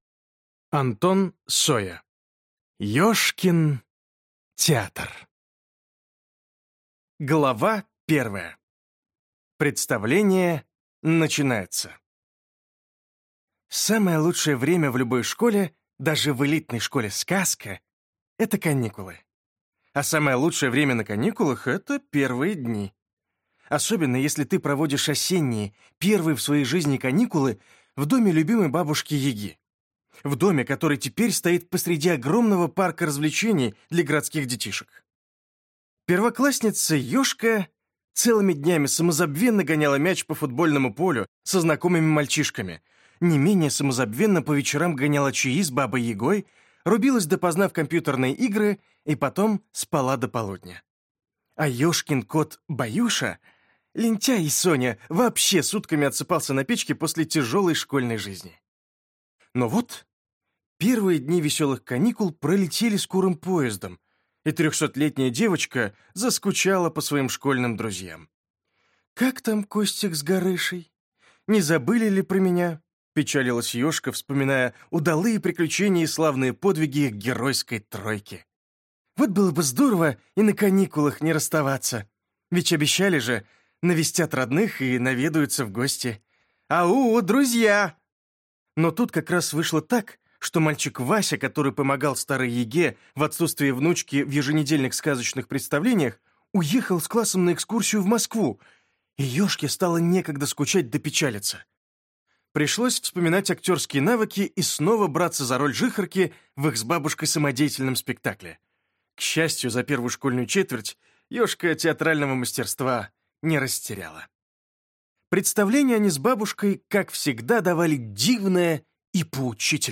Аудиокнига Ёжкин театр | Библиотека аудиокниг